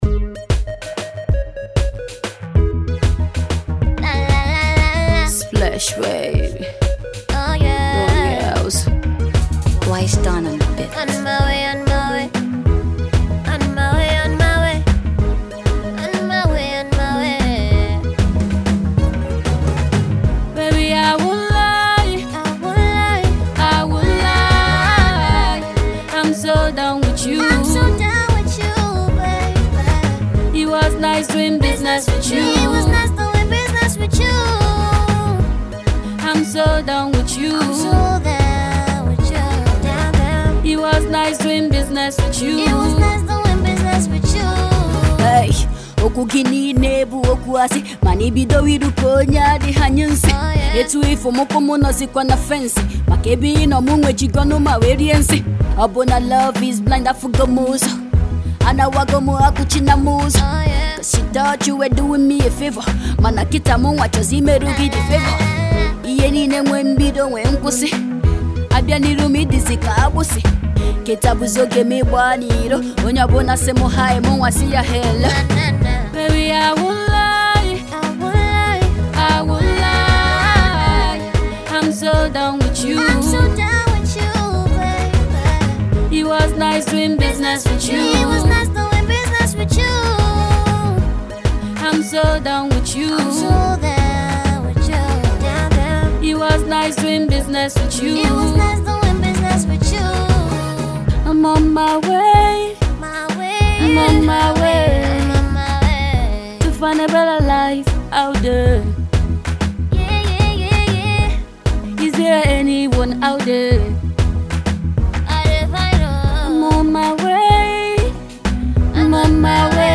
Nigeria’s rap sensation